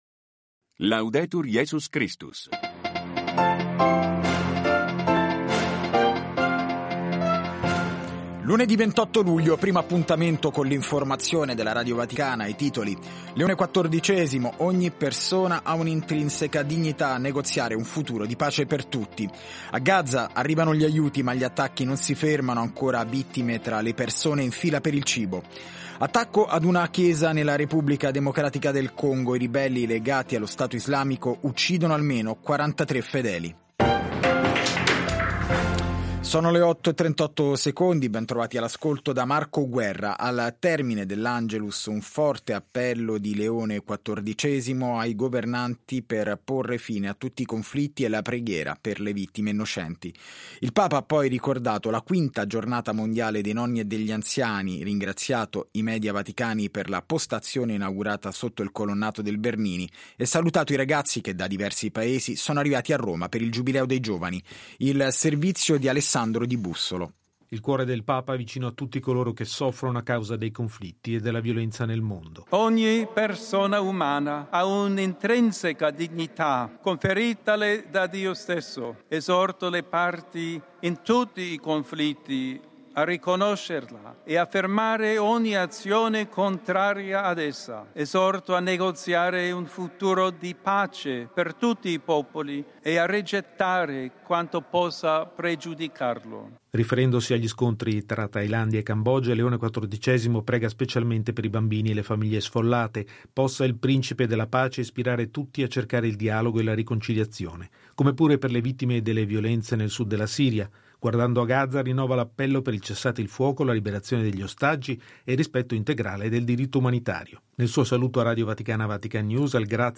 … continue reading 166 episodes # vatican city # Cattolici # Società # Religione # Cristianesimo # San Pietro # Notizie # Radio Vaticana Vatican News